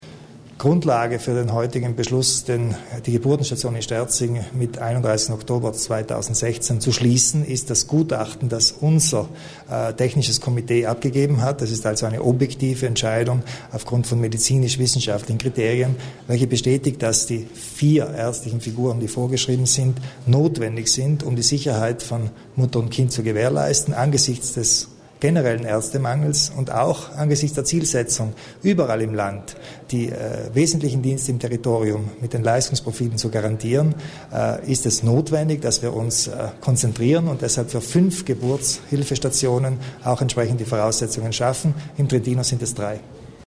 Landeshauptmann Kompatscher zur Zukunft der Geburtshilfe in Sterzing